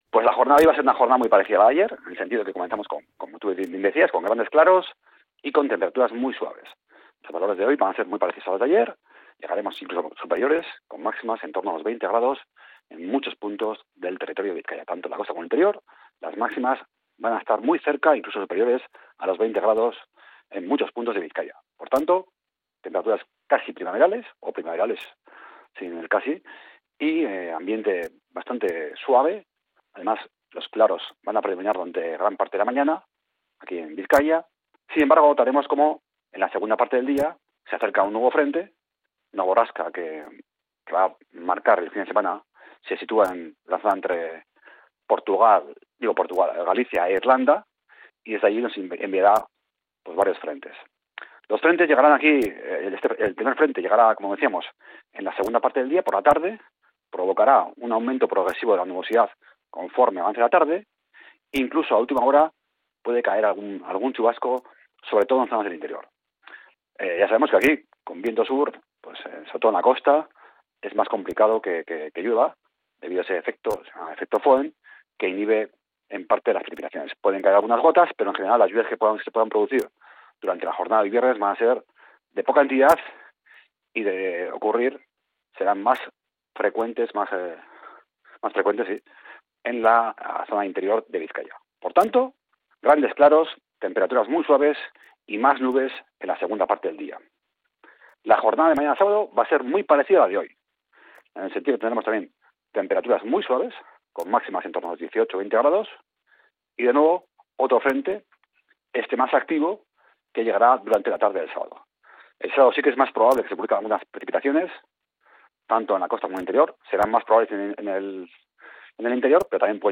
El pronóstico del tiempo en Bizkaia para este 7 de marzo